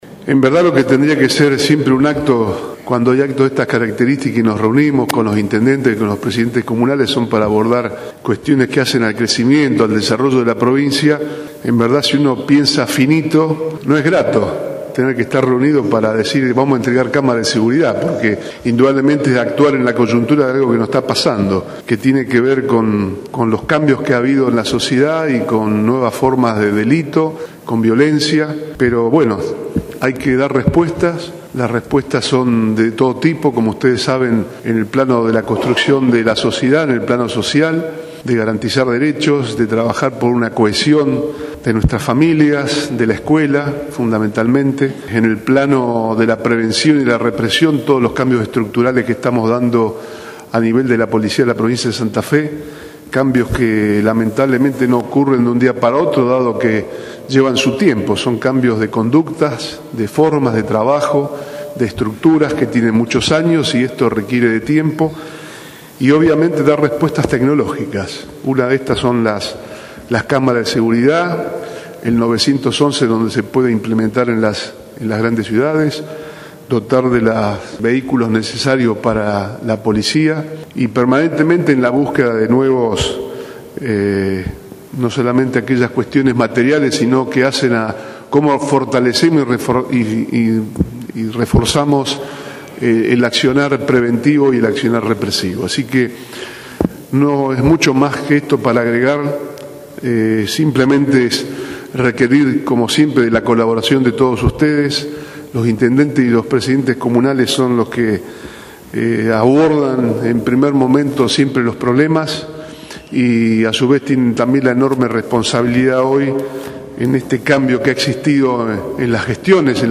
El acto se realizó en el Salón Blanco de Casa de Gobierno, con la presencia además del ministro de Seguridad, Raúl Lamberto; y del secretario de Estado de la Energía, Jorge Álvarez,